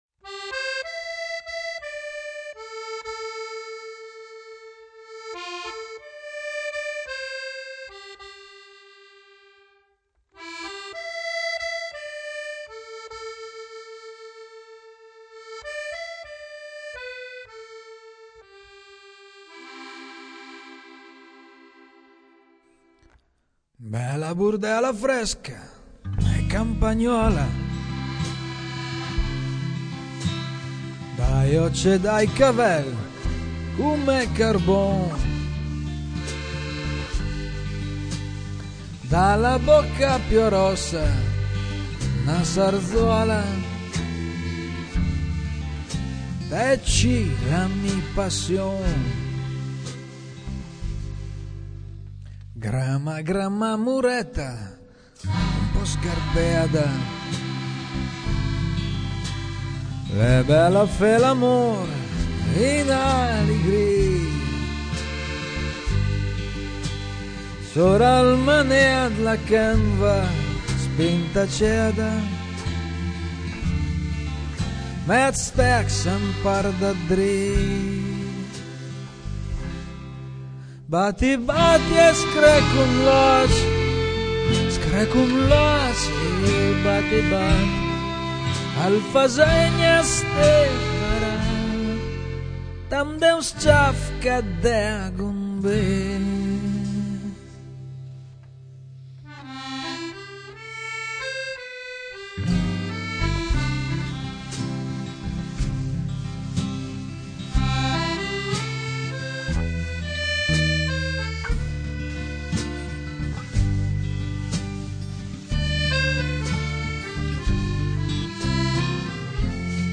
GenereWorld Music / Folk
voce
piano, fisarmonica, basso, tastiere, percussioni e cori
chitarra e violino
chitarra e cori
batteria e percussioni